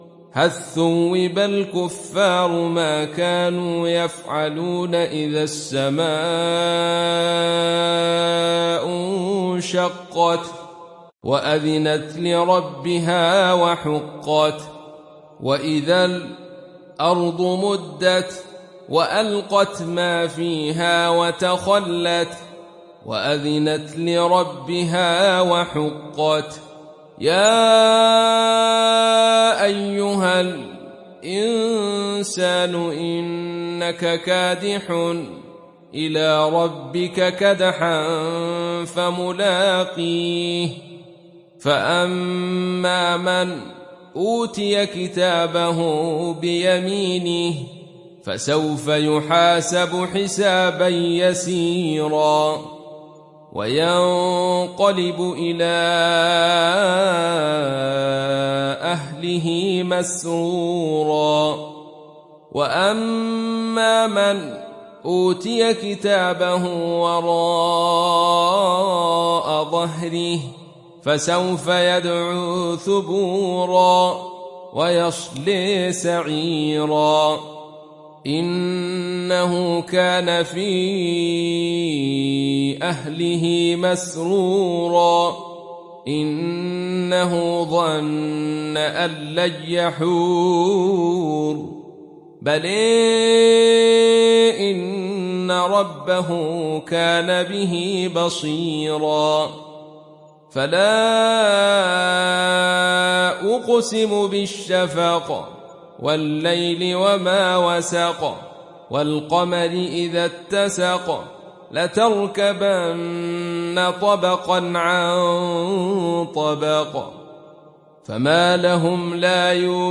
Surah Al Inshiqaq Download mp3 Abdul Rashid Sufi Riwayat Khalaf from Hamza, Download Quran and listen mp3 full direct links